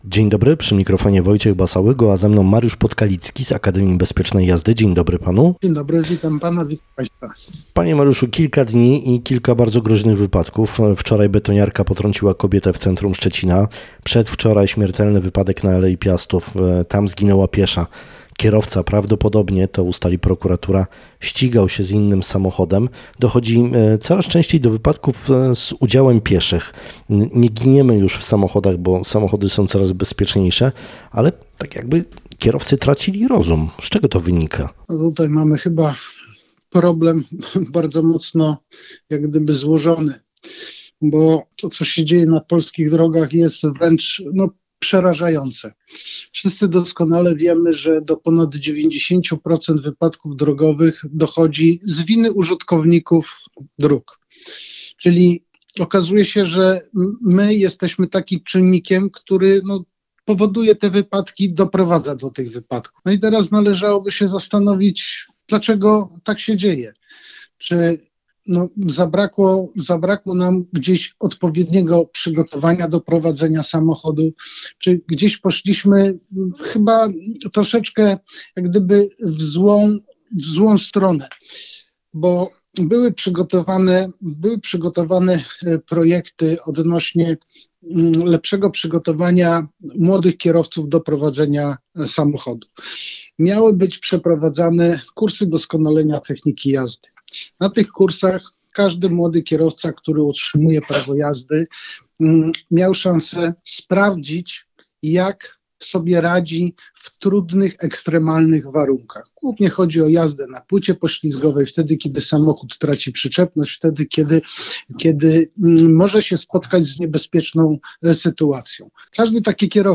– Swoje zdanie wyraża nasz dzisiejszy gość.